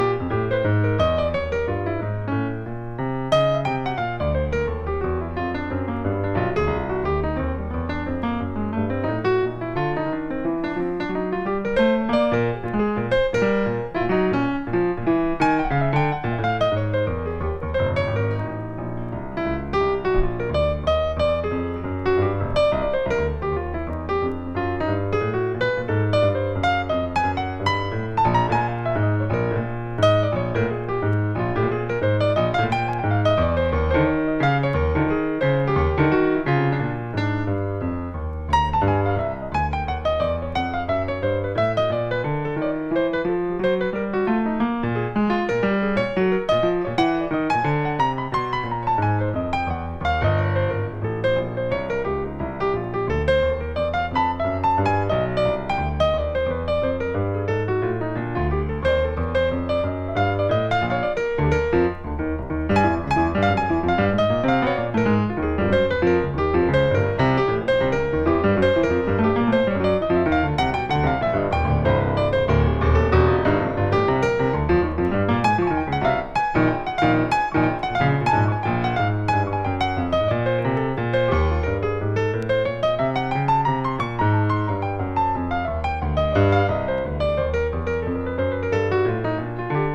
• JAZZ
共通のテーマ曲、カヴァー曲、オリジナル曲を3曲ずつ収録したPCM録音盤。
和ジャズ